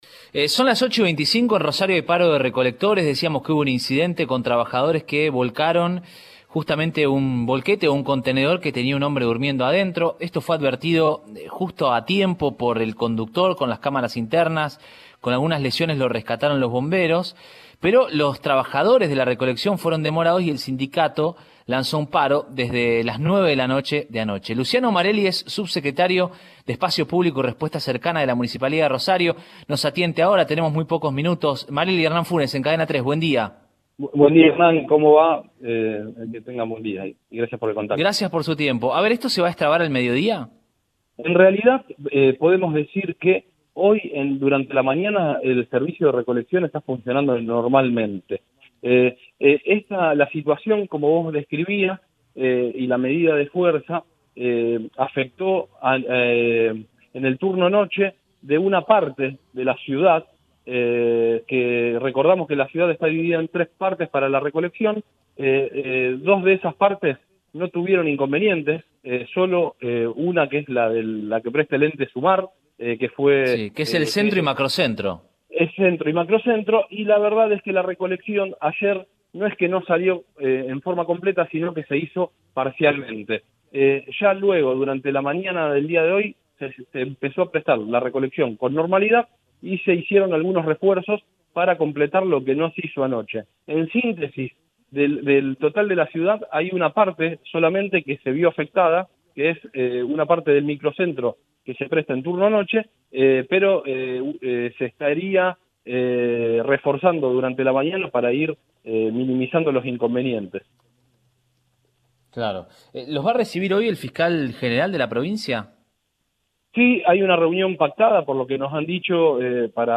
Podemos decir que durante la mañana el servicio de recolección funciona normalmente”, confirmó en Radioinforme 3, por Cadena 3 Rosario, Luciano Marelli, subsecretario de Espacio Público de la Municipalidad de Rosario.